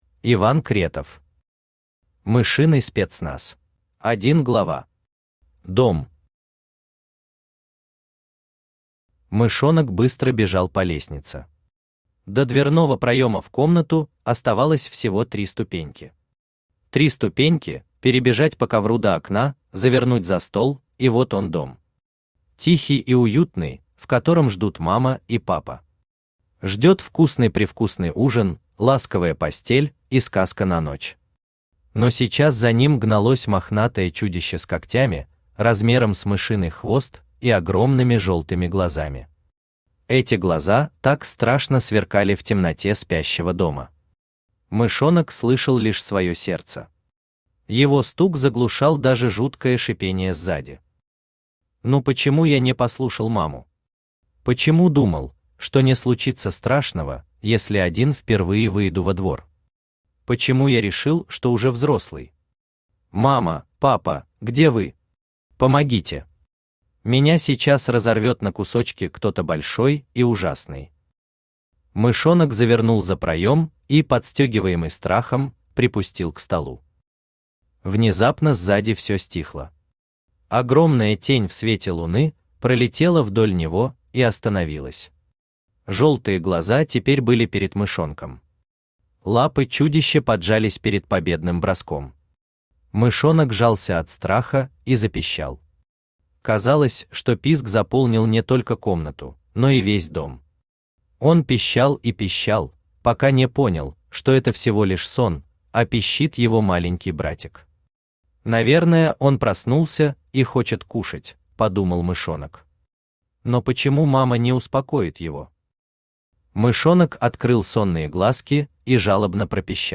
Аудиокнига Мышиный спецназ | Библиотека аудиокниг
Aудиокнига Мышиный спецназ Автор Иван Владимирович Кретов Читает аудиокнигу Авточтец ЛитРес.